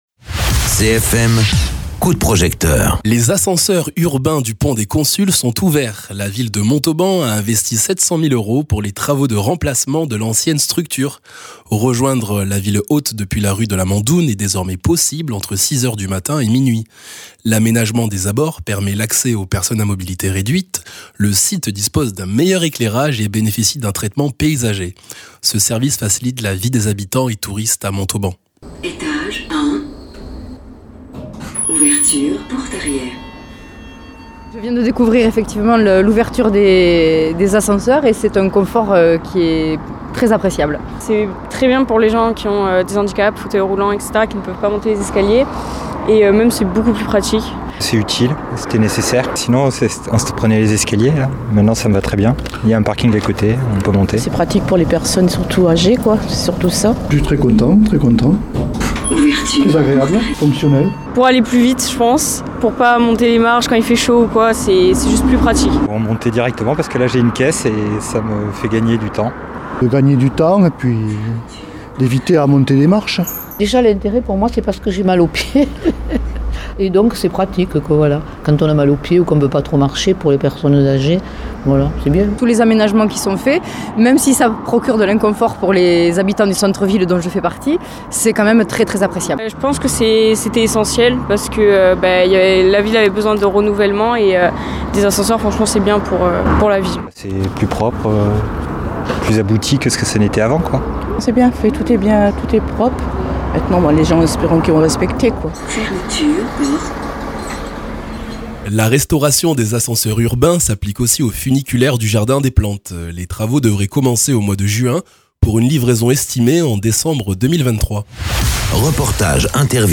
Interviews
Invité(s) : Micro-trottoir réalisé auprès des utilisateurs des ascenseurs urbains du Pont des Consuls.